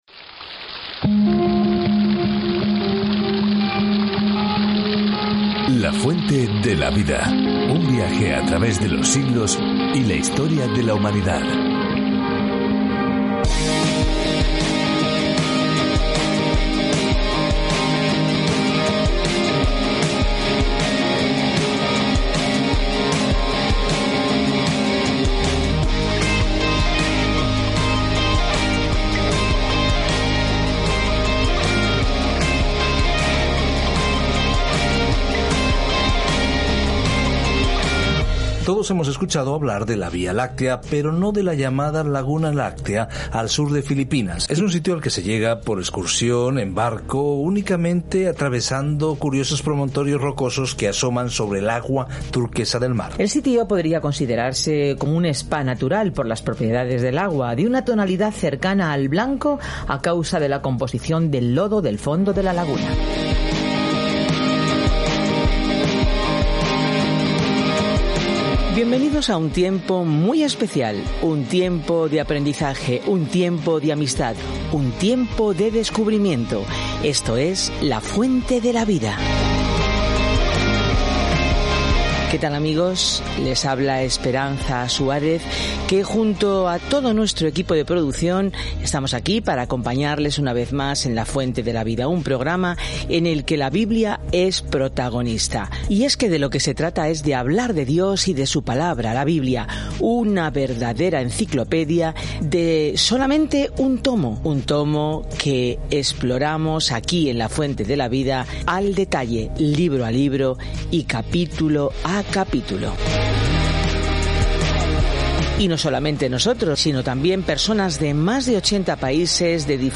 Escritura 1 REYES 21 1 REYES 22:1-15 Día 14 Iniciar plan Día 16 Acerca de este Plan El libro de Reyes continúa la historia de cómo el reino de Israel floreció bajo David y Salomón, pero finalmente se dispersó. Viaje diariamente a través de 1 Reyes mientras escucha el estudio de audio y lee versículos seleccionados de la palabra de Dios.